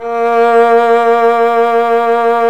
Index of /90_sSampleCDs/Roland L-CD702/VOL-1/STR_Violin 1-3vb/STR_Vln1 % marc